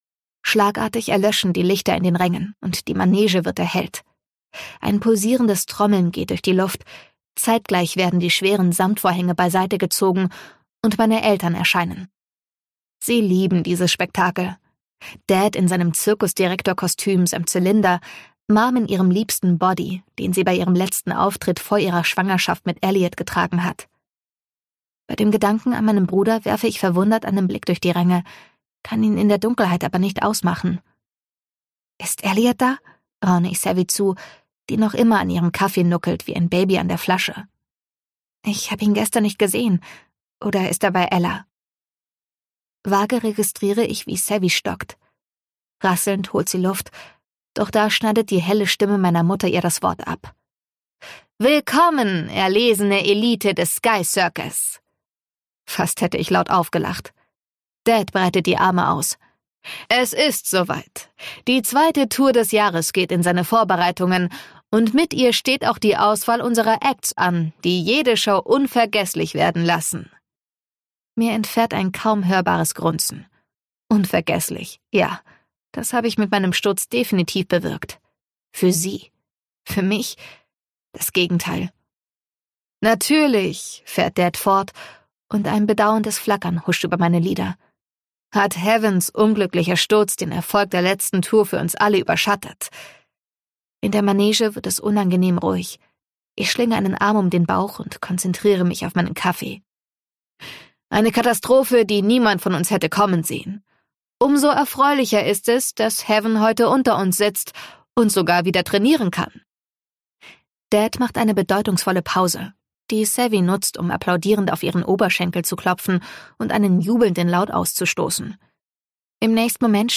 Ausgabe: Ungekürzte Lesung